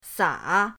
sa3.mp3